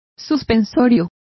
Complete with pronunciation of the translation of suspensory.